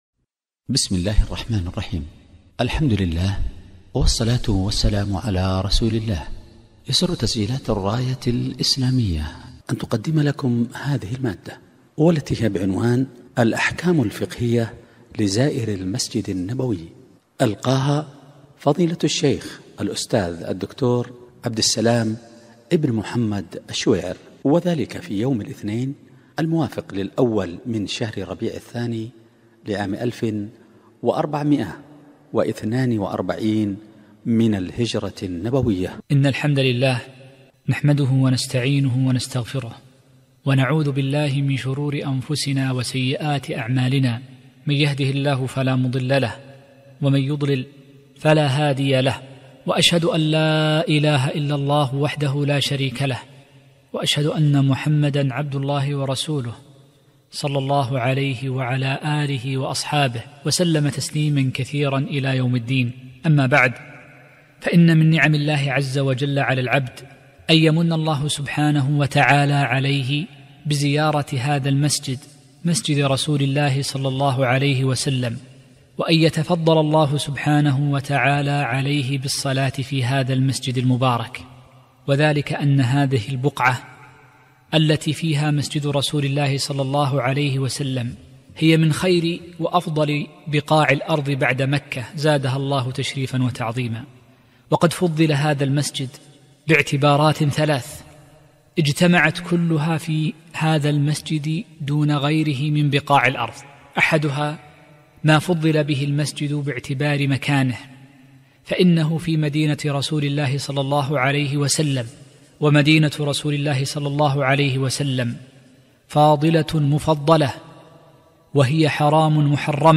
محاضرة - الأحكام الفقهية لزائر المسجد النبوي